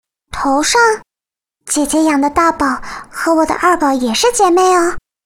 贡献 ） 协议：Copyright，人物： 碧蓝航线:平海语音 2020年8月13日